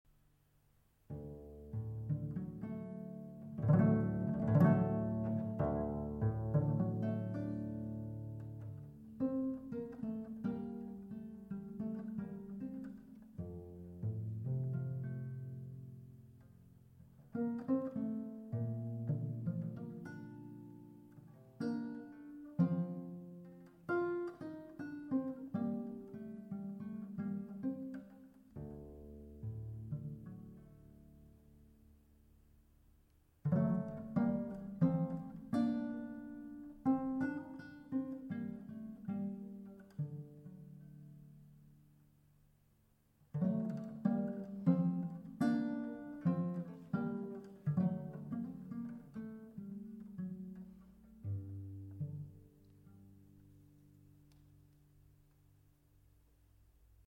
Baroque Ensemble